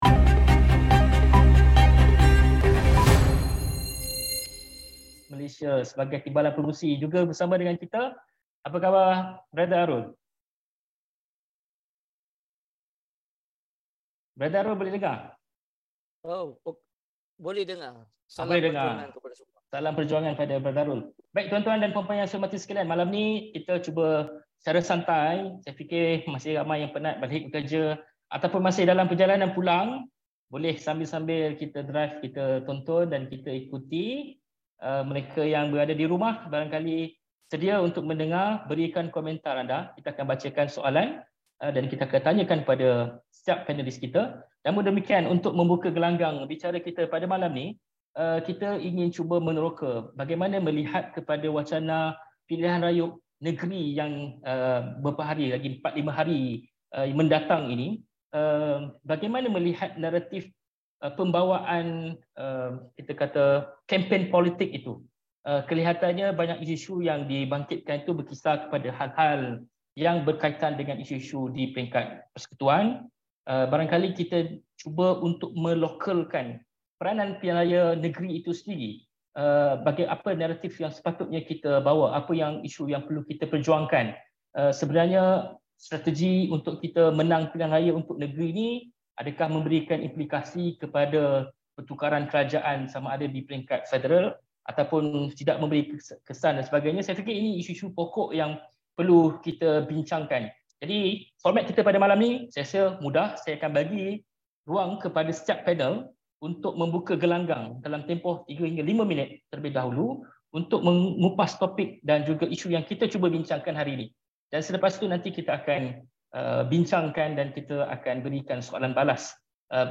Ikuti forum BERSIH X Astro AWANI - PRN : Referendum PMX Atau Kerajaan Negeri?